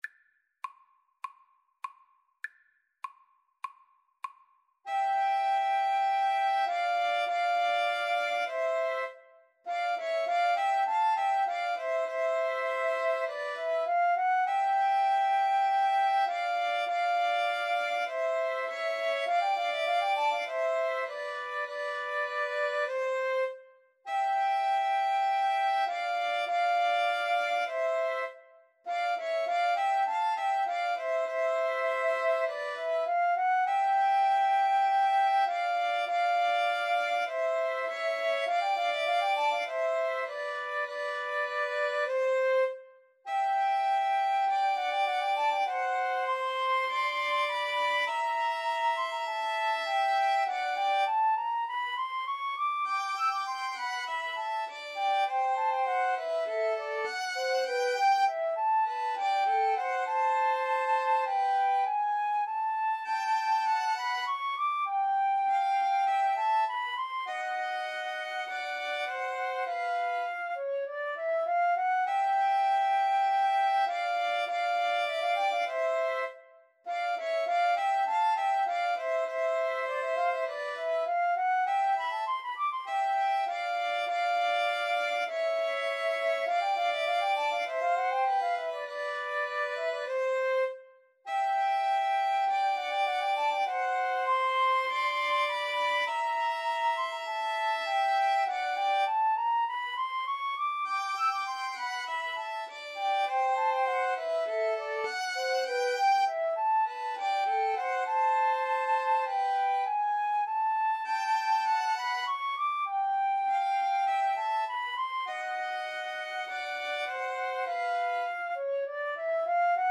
4/4 (View more 4/4 Music)
Classical (View more Classical flute-violin-cello Music)